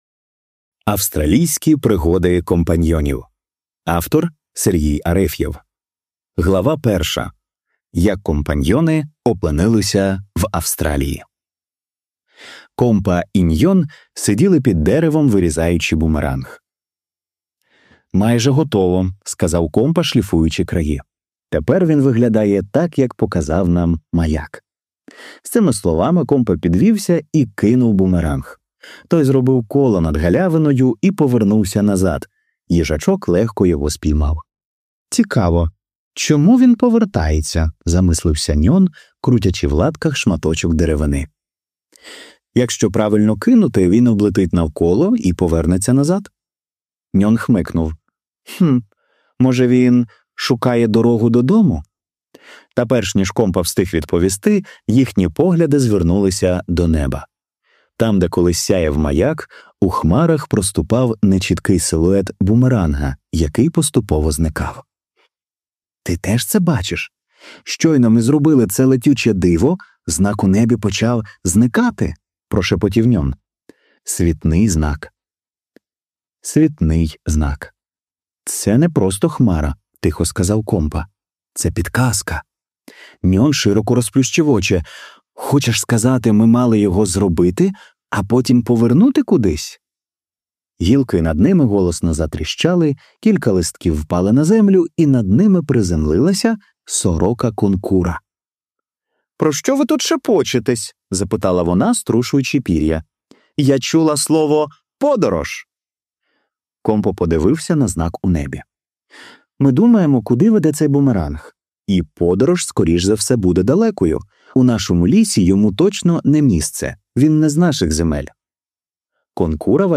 Аудіоказка Австралійські пригоди компаньйонів